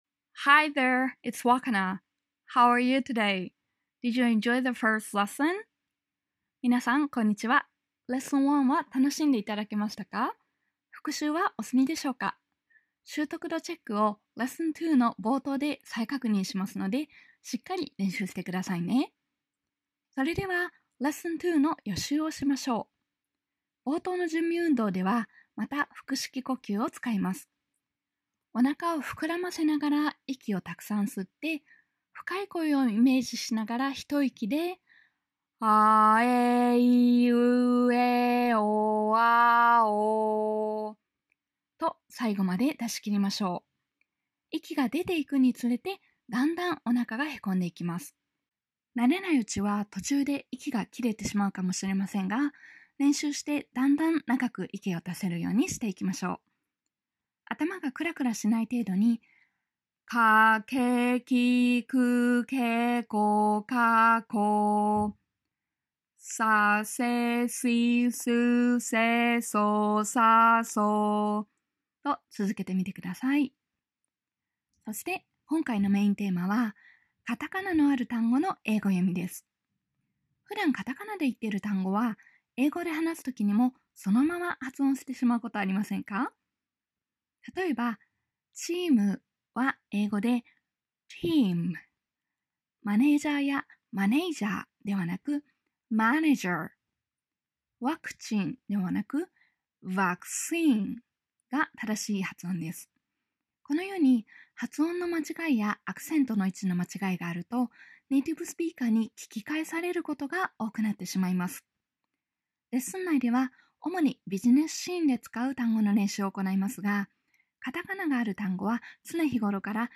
Lesson2予習音声 Lessonを最大限活用するため、次回のレッスンのポイントを確認しておきましょう。